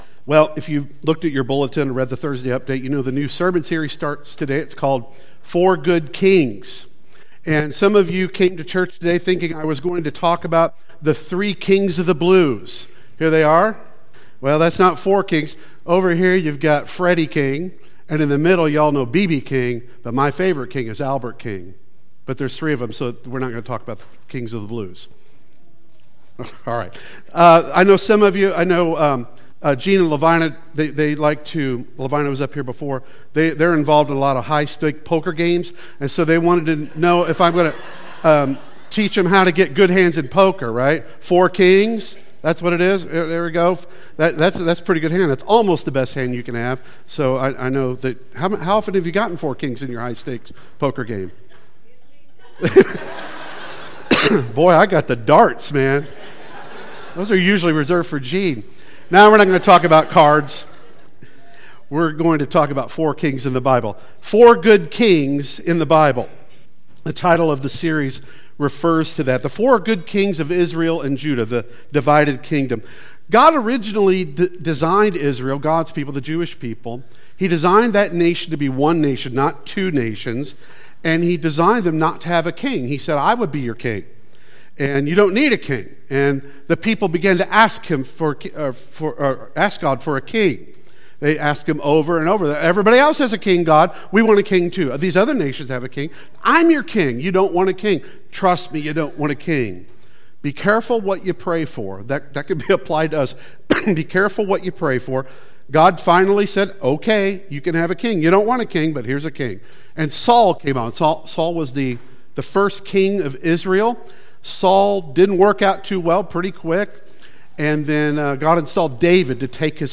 Four Good Kings Service Type: Sunday Worship Service Speaker